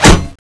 thanatos3_metal1.wav